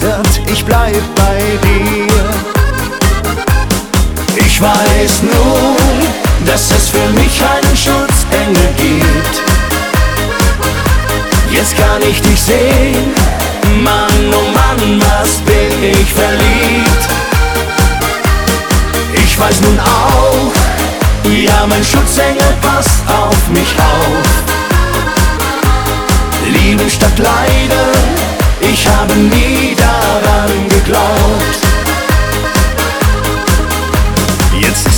Жанр: Поп музыка
German Pop